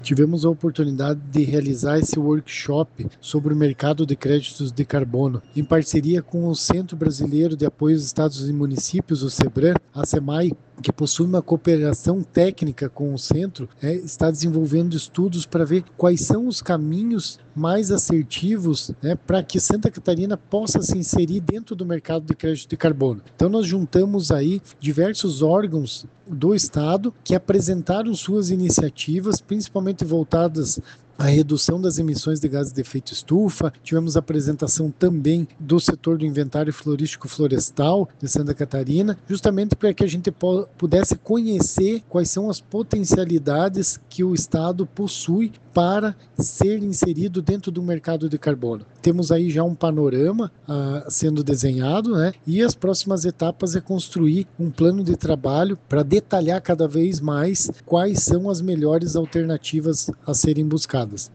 O secretário de Estado do Meio Ambiente e da Economia Verde, Guilherme Dallacosta, ressalta a importância de avançar nos debates para inserir ainda mais Santa Catarina no mercado de crédito de carbono: